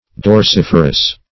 Meaning of dorsiferous. dorsiferous synonyms, pronunciation, spelling and more from Free Dictionary.